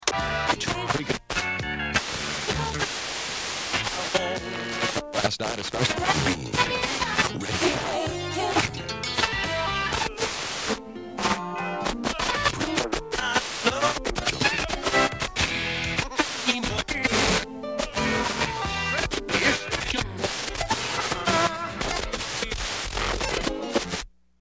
Звуки радио, радиопомех
Погрузитесь в атмосферу ретро-радио с коллекцией звуков помех, шипения и настройки частот.
Быстрая перемотка FM-станций на домашнем радиоприемнике